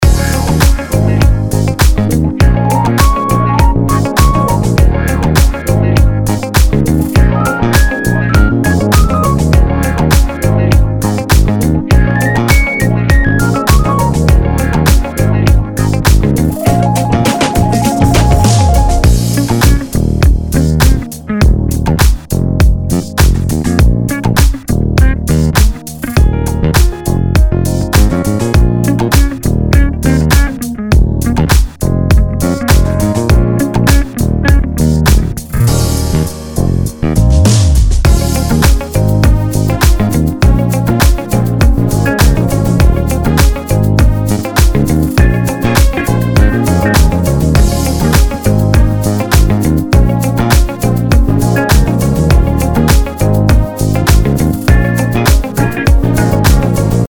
Сайдчейн на басе, и без.
Мне у тебя баланс не нравится, хэты громкие (сравни даже со своими видео), бочка стилистически не вписывается, какая-то клубная вышла, и это при том что пинка ей все так же не хватает, атаки так же многовато атаки на мой вкус, клэп тоже сам по себе.